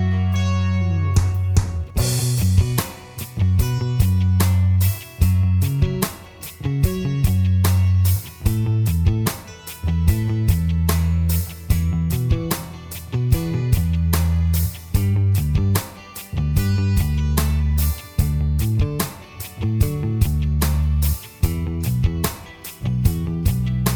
No Lead Guitar Soft Rock 6:35 Buy £1.50